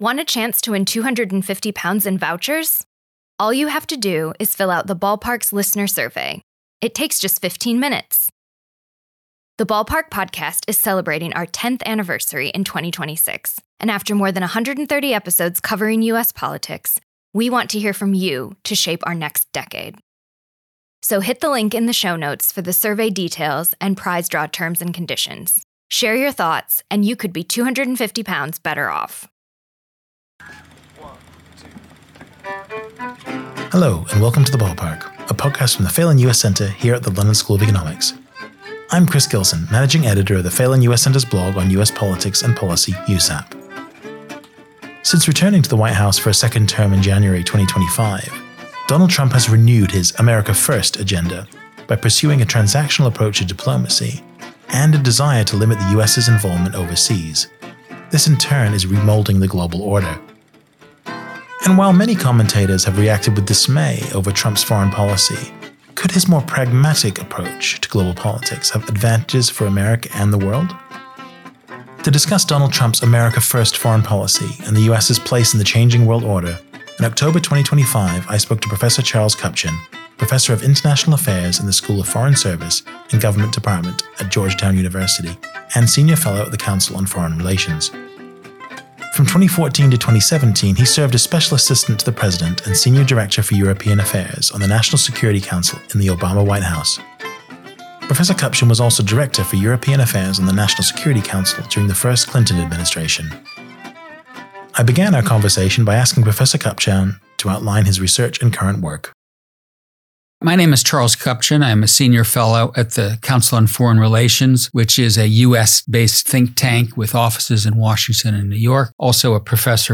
To discuss Donald Trump’s "America First" foreign policy and the US’ place in the changing world order, in October 2025 the Phelan US Centre spoke to Professor Charles Kupchan, Professor of International Affairs in the School of Foreign Service and Government Department at Georgetown University, and Senior Fellow at the Council on Foreign Relations.